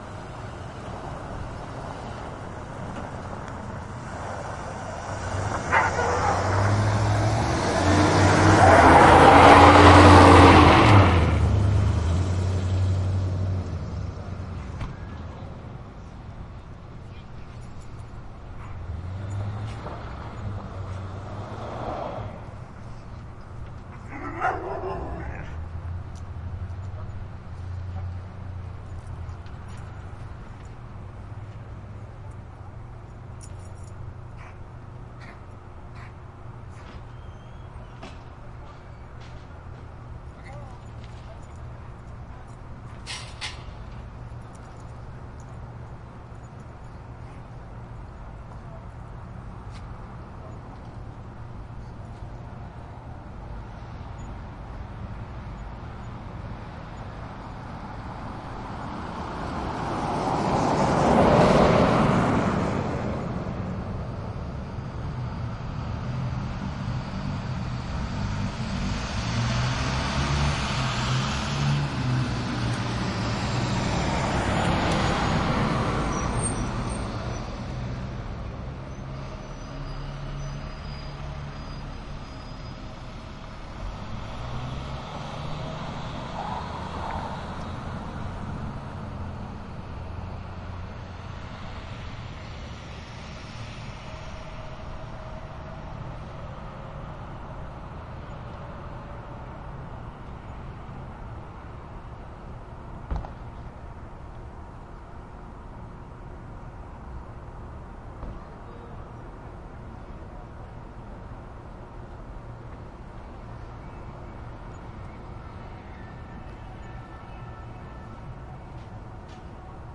皮卡 " 皮卡 福特62开走了
描述：1962年福特皮卡车驶离泥土和碎石路。
Tag: 卡车 皮卡 污垢 驾驶 关闭